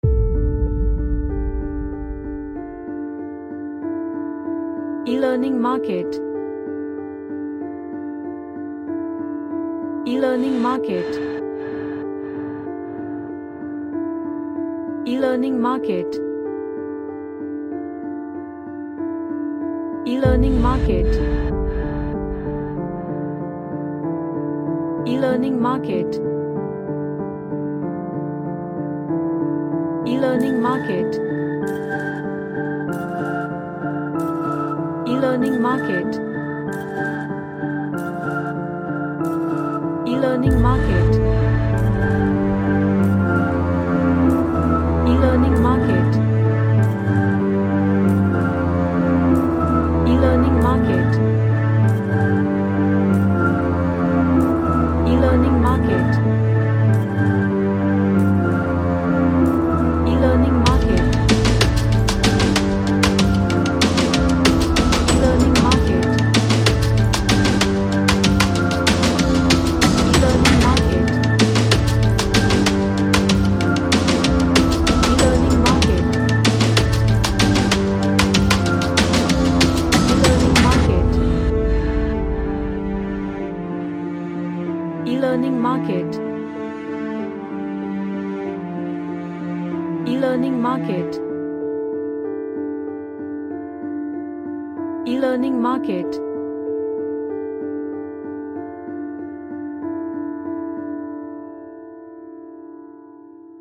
An emotional orchestral Track
Emotional